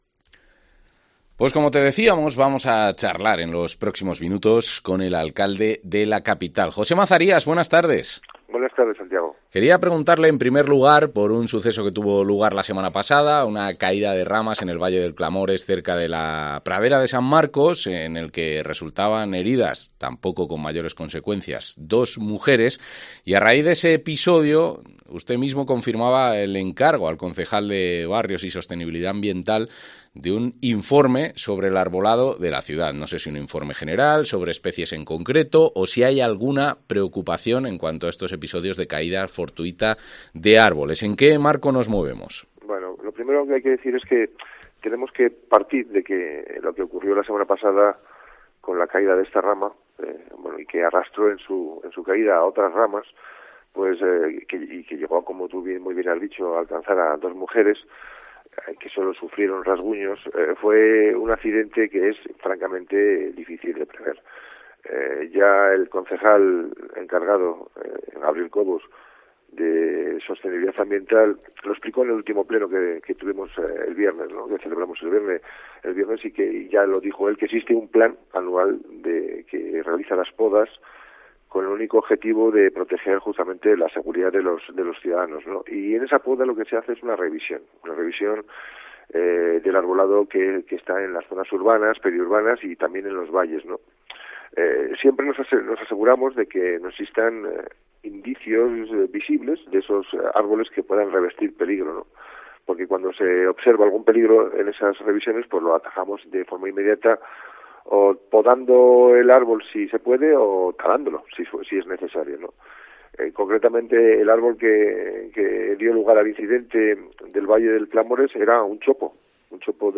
José Mazarías, alcalde de Segovia